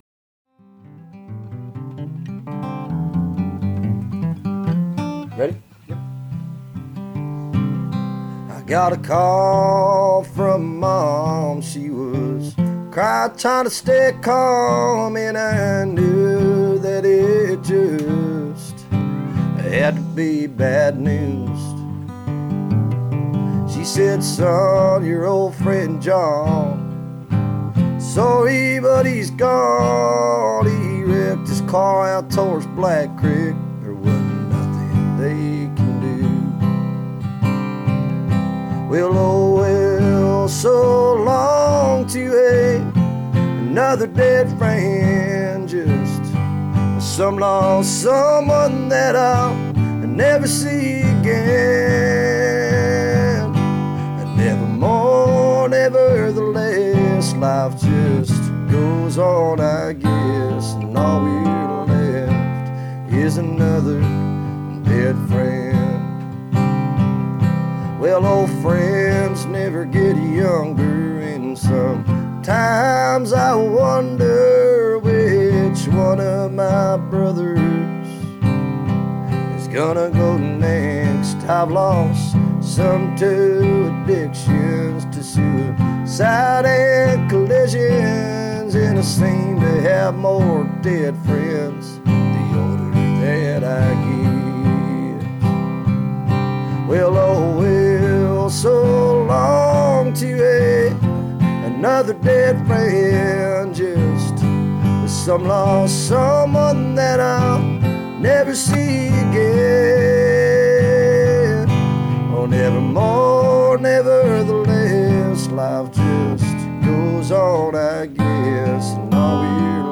Virtually a who's who of the Americana/Roots world today.